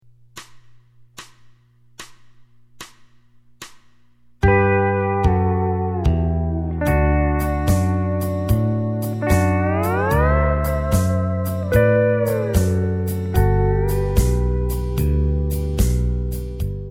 This intro in the key of G demonstrates the use of the
G harmonized scale on strings 5 and 6. The lick begins
with three pickup notes, then starts on
5 chord (D) and then resolves to 1 (G).